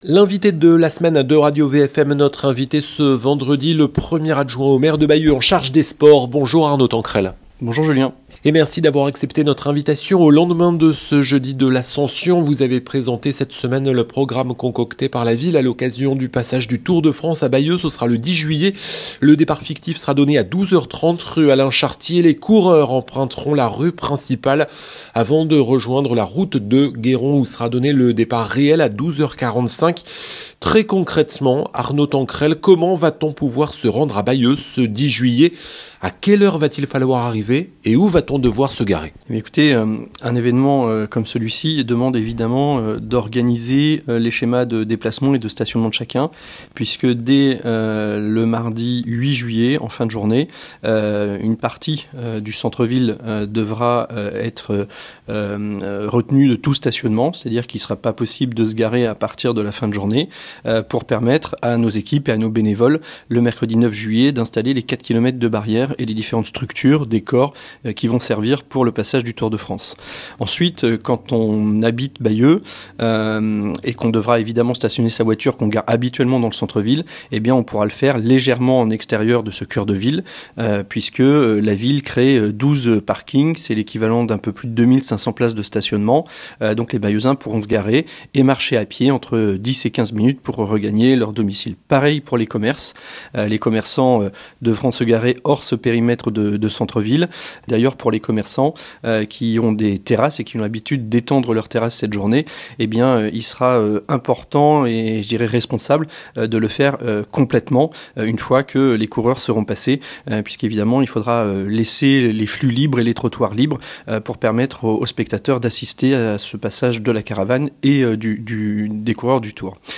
Arnaud Tanquerel Arnaud Tanquerel, premier adjoint au maire de Bayeux en charge des sports est l'invité de Radio VFM ce vendredi 30 mai 2025 à 8 h 20